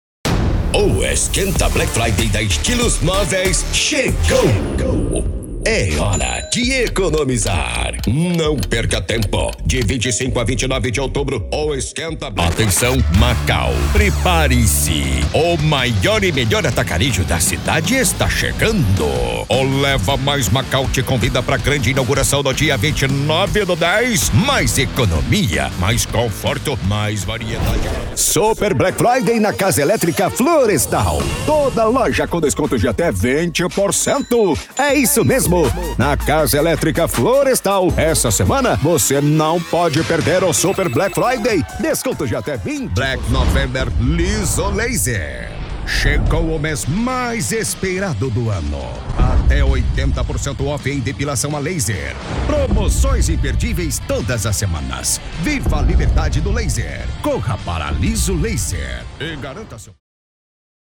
Demo Black Friday - Locução Impacto varejo (Ricardo eletro):